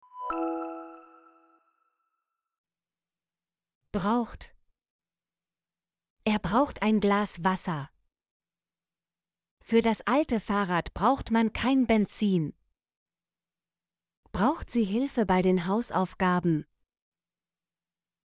braucht - Example Sentences & Pronunciation, German Frequency List